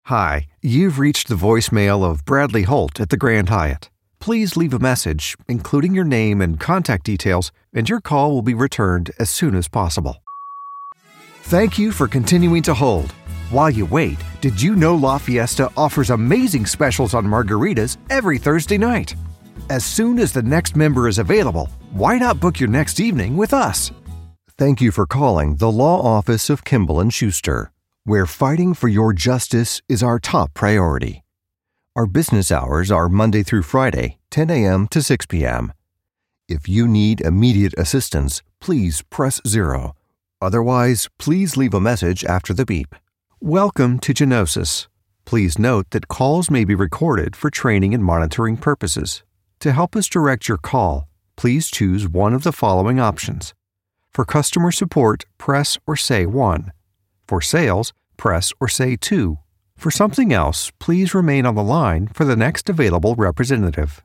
Anglais (Américain)
Commerciale, Fiable, Corporative, Accessible, Amicale
Téléphonie